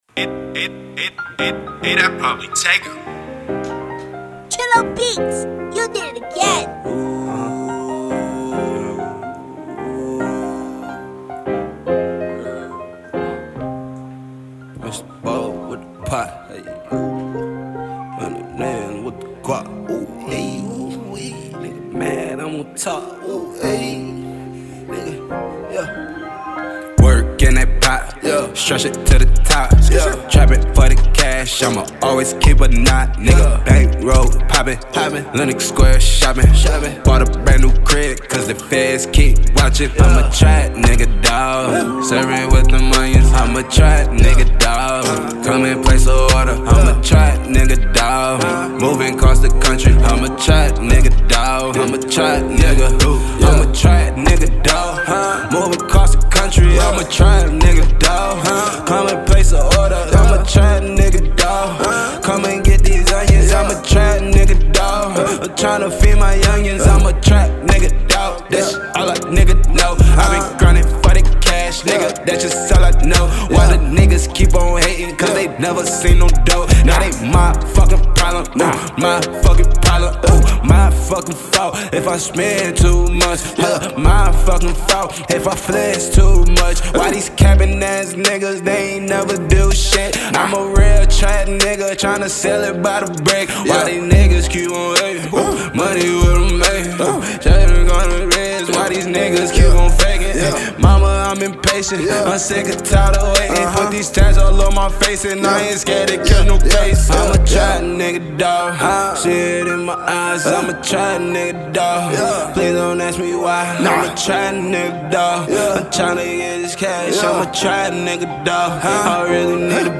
'Hip-Hop / Rap'